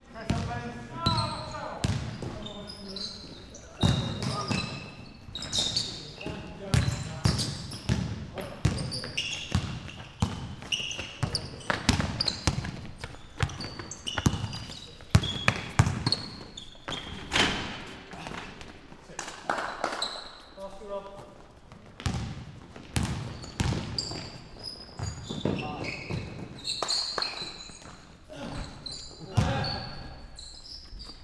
Basketball game interior